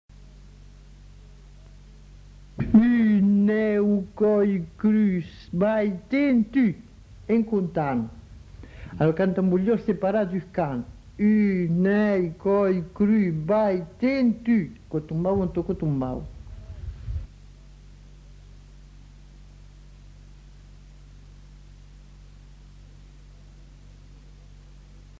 Formulette enfantine
Aire culturelle : Haut-Agenais
Genre : forme brève
Effectif : 1
Type de voix : voix d'homme
Production du son : récité